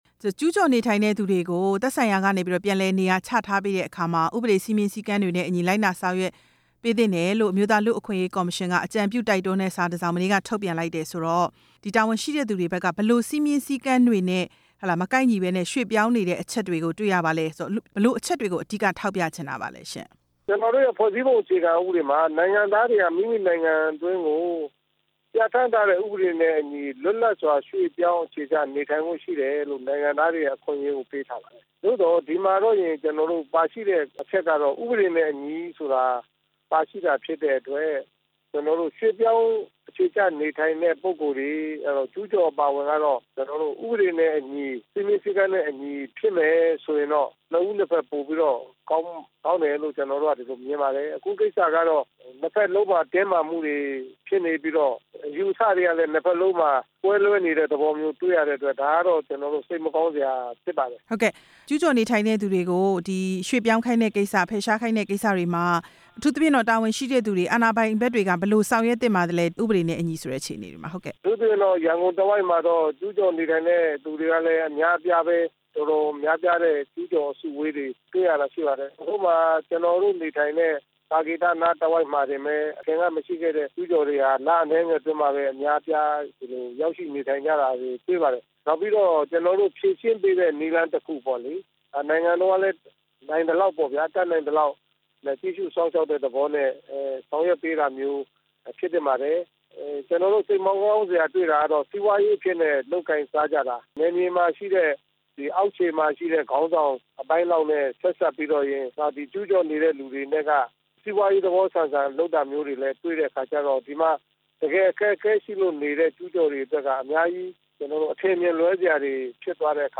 ကျူးကျော်တွေအရေး တိုက်တွန်းလိုက်တဲ့ လူ့အခွင့်အရေးကော်မရှင်နဲ့ မေးမြန်းချက်